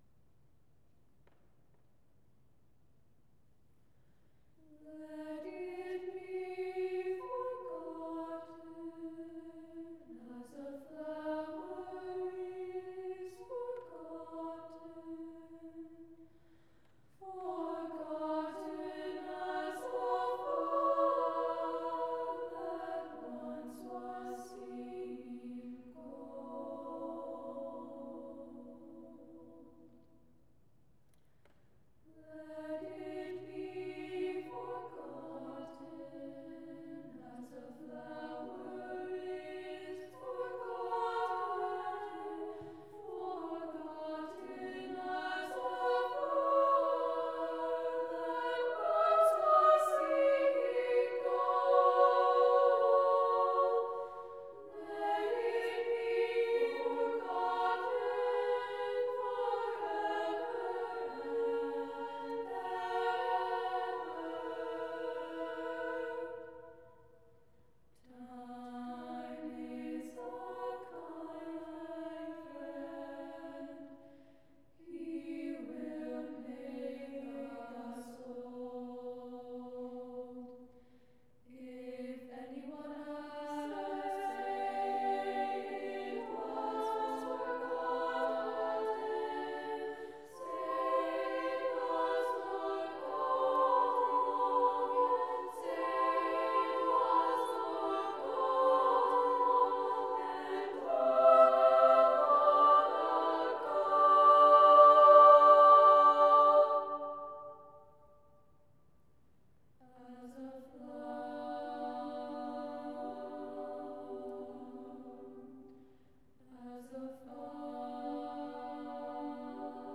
for SA div. a cappella